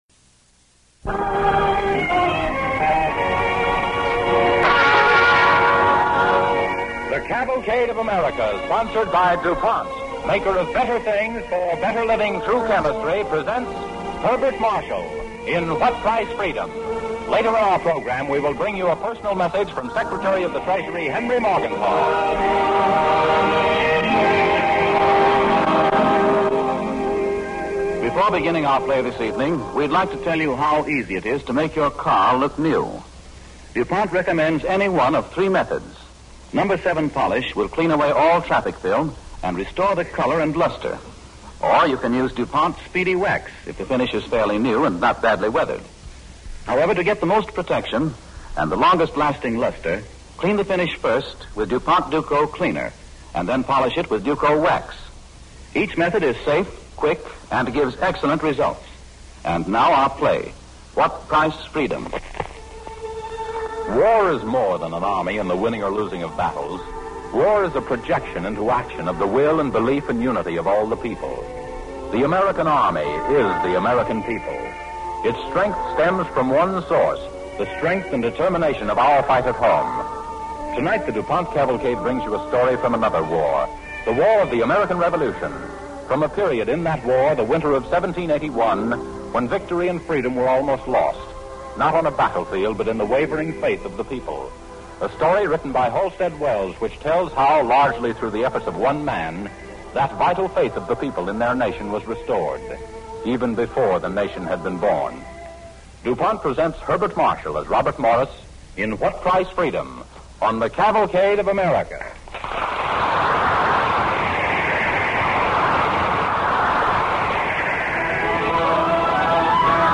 What Price Freedom?, starring Herbert Marshall and Herbert Rawlinson